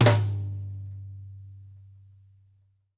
SNARE+MED -L.wav